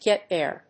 アクセントgét thère 《口語》